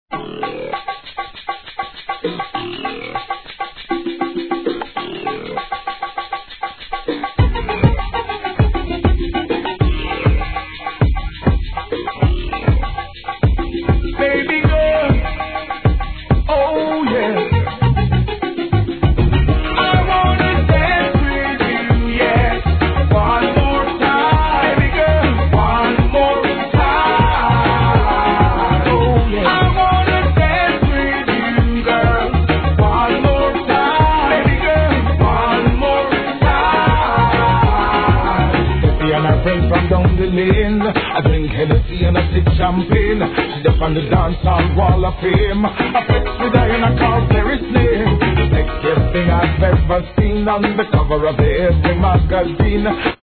REGGAE
キャッチーに仕上げてます!